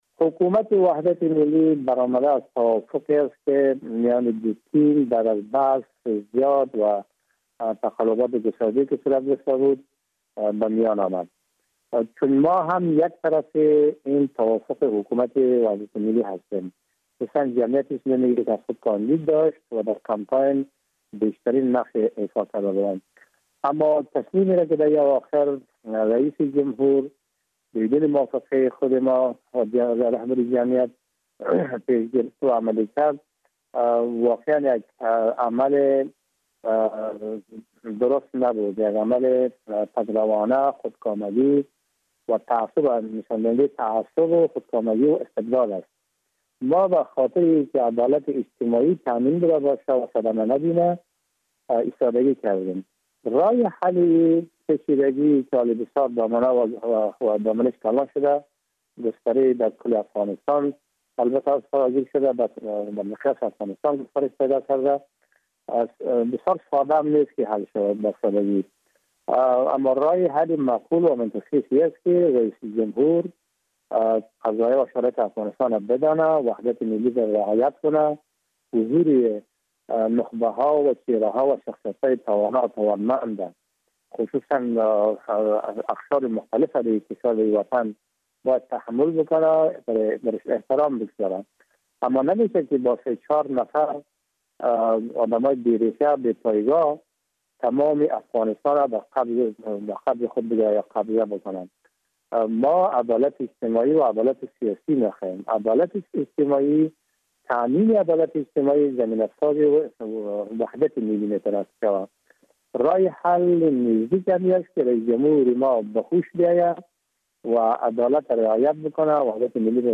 Атомуҳаммади Нур – волии Балх – дар суҳбати ихтисосӣ бо бахши тоҷикии Радиои Озодӣ гуфт, ки ба шимоли Афғонистон “кӯчидан”-и ноамниҳо талоше барои наздик шудани ҷангҷӯён ба марзи кишварҳои Осиёи Марказист.